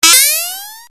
Sonic Spike Effect Sound Button: Unblocked Meme Soundboard